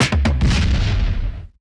Drum Fill 2.wav